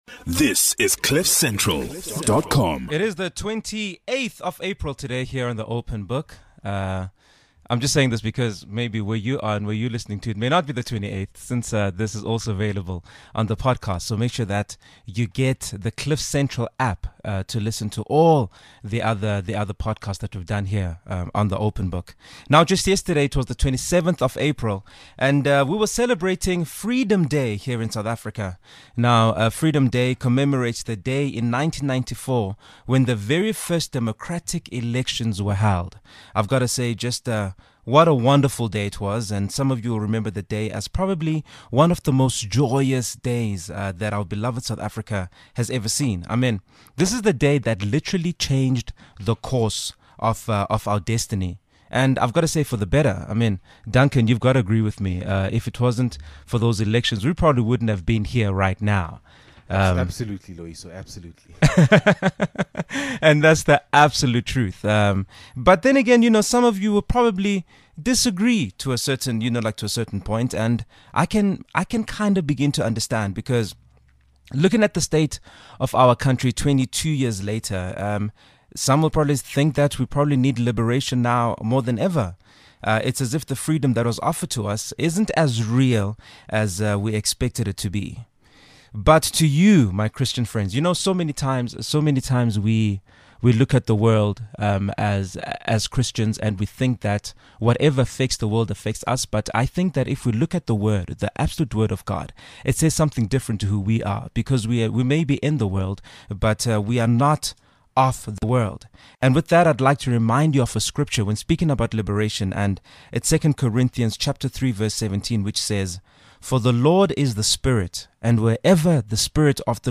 Play Rate Apps Listened List Bookmark Share Get this podcast via API From The Podcast The Open Book Hosted by Loyiso Bala.